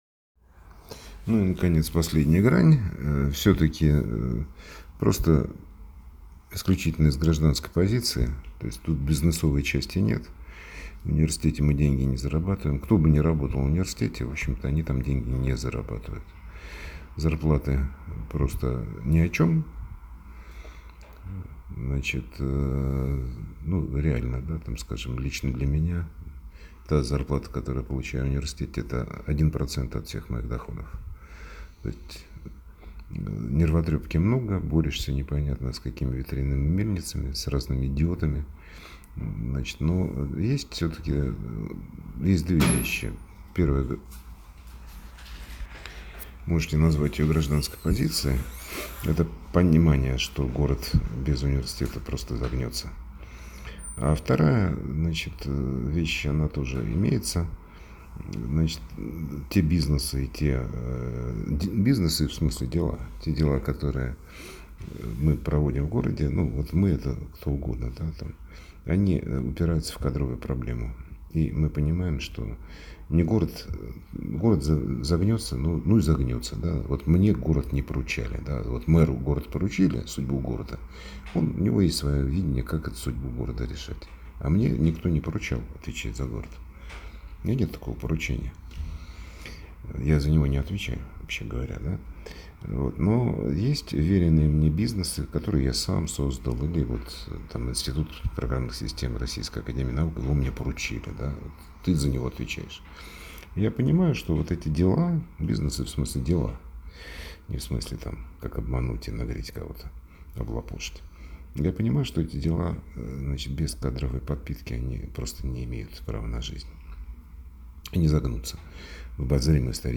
Прямая речь ректора университета